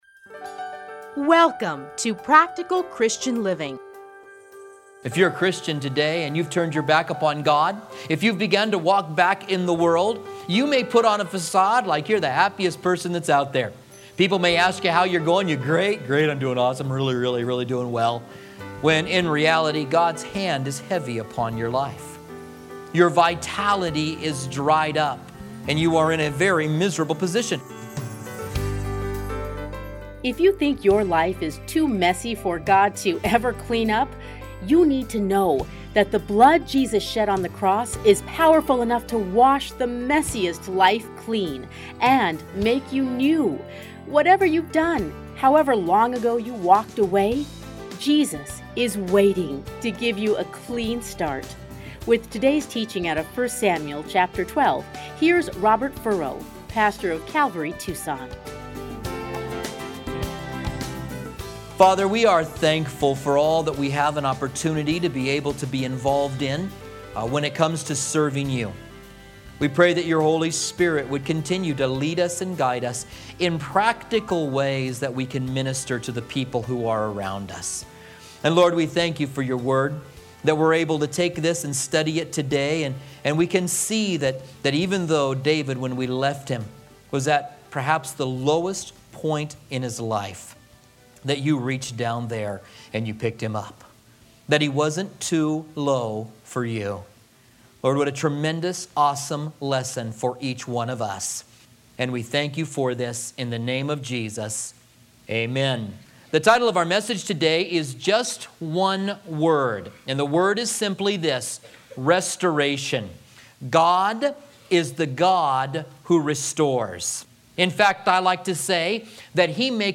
radio programs